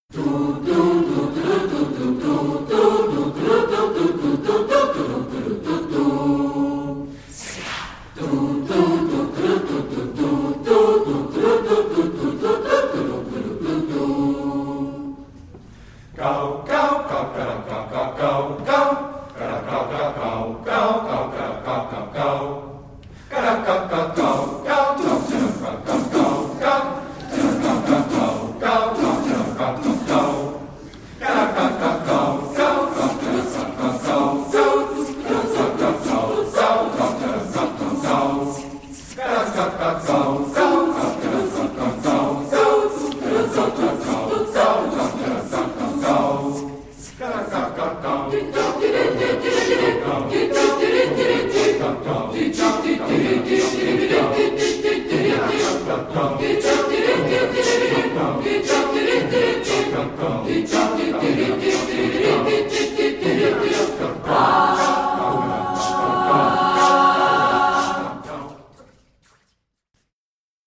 a Cappella
SMATB